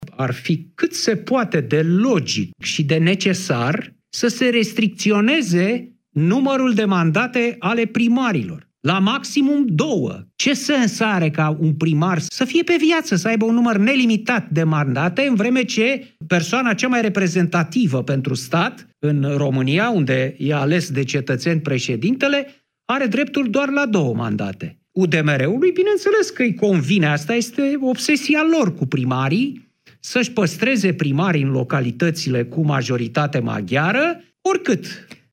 Cristian Tudor Popescu a comentat la Europa FM și propunerea UDMR de a prelungi mandatele aleșilor locali de la patru la cinci ani.